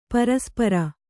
♪ paraspara